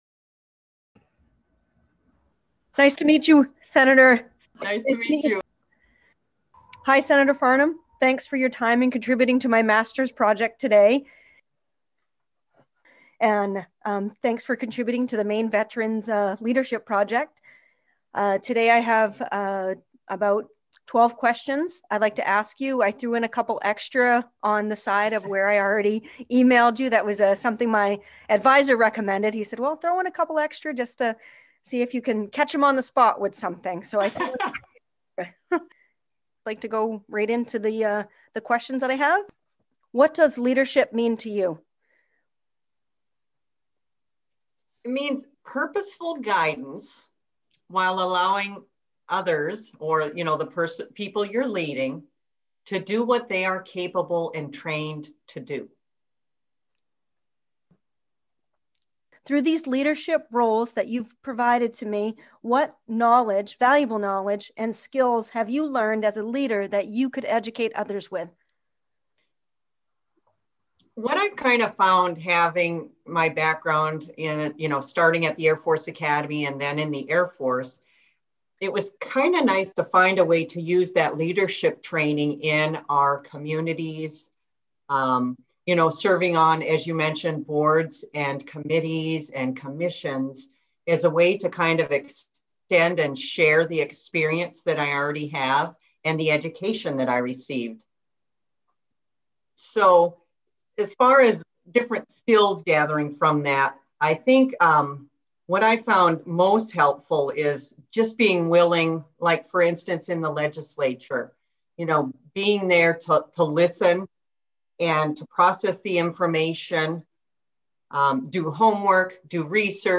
Interview text with Capt (ret) Nichi Farnham